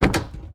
car-door-open-1.ogg